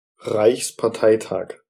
Ääntäminen
Synonyymit parti politique Ääntäminen France: IPA: [œ̃ paʁ.ti] Tuntematon aksentti: IPA: /paʁ.ti/ Haettu sana löytyi näillä lähdekielillä: ranska Käännös Ääninäyte Substantiivit 1.